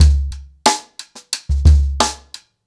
Wireless-90BPM.3.wav